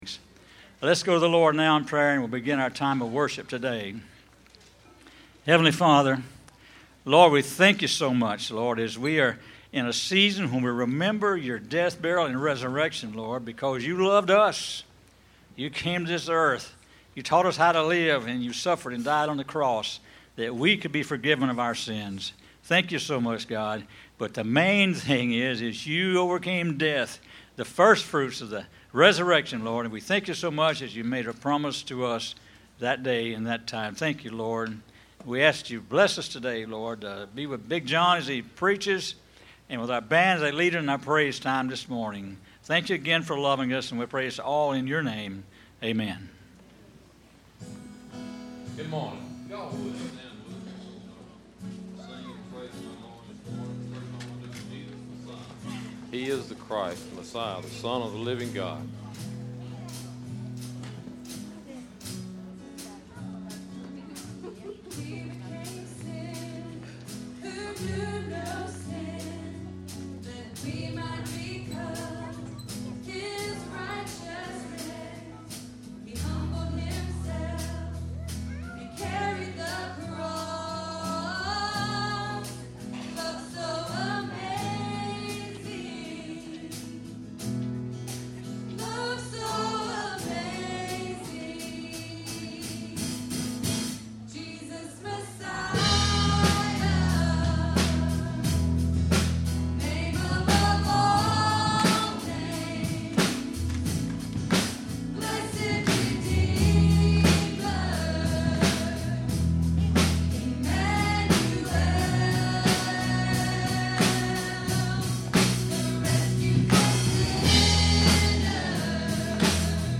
Listen to Jesus Grace Under Fire - 03_20_16_sermon.mp3